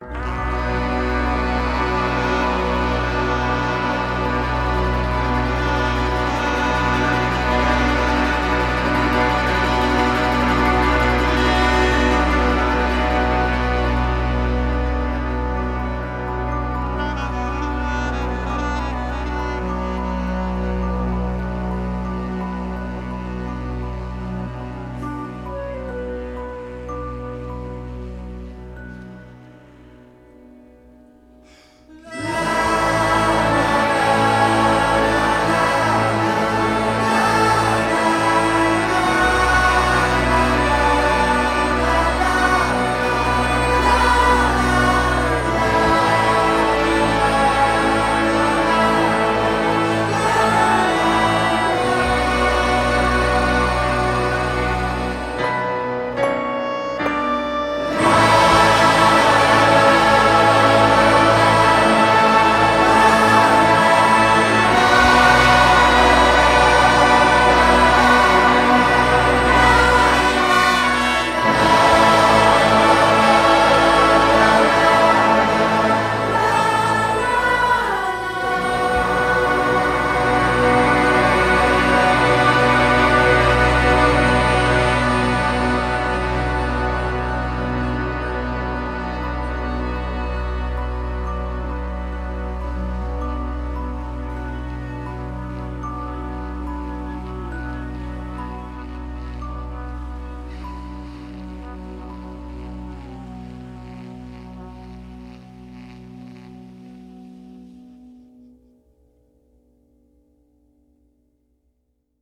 Genre: Indie-Pop / Folk